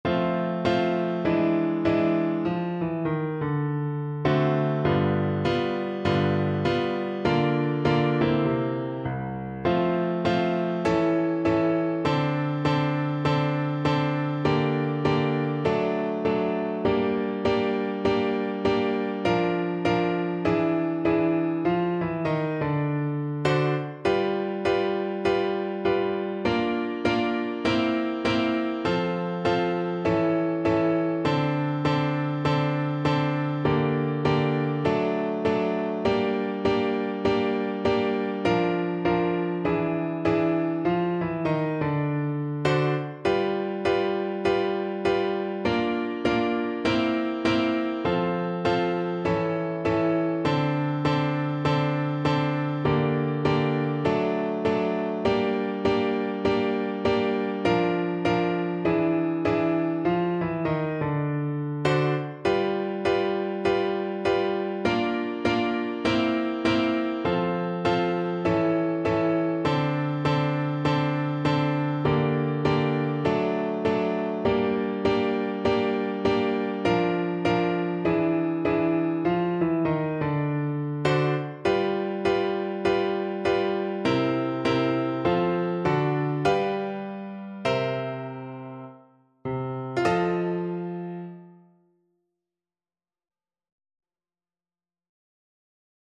Free Sheet music for Soprano (Descant) Recorder
4/4 (View more 4/4 Music)
Moderato
D6-E7
Recorder  (View more Intermediate Recorder Music)
Pop (View more Pop Recorder Music)